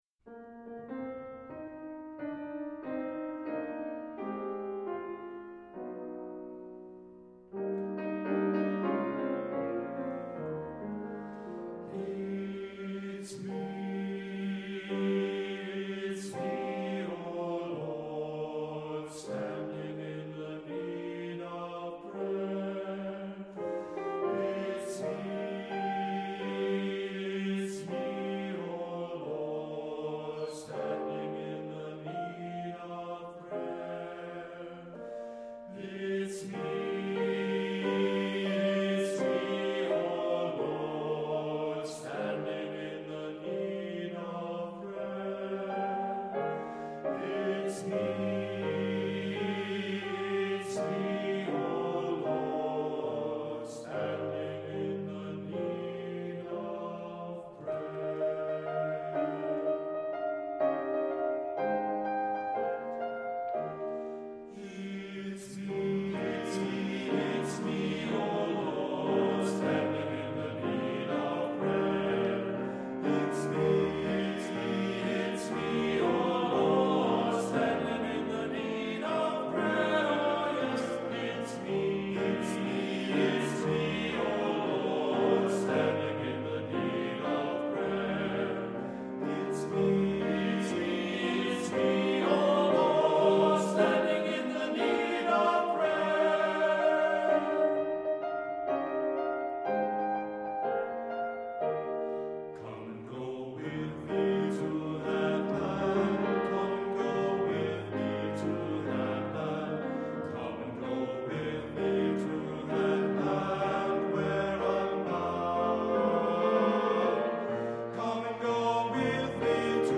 Voicing: Two-part equal